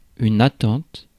Ääntäminen
IPA: /a.tɑ̃t/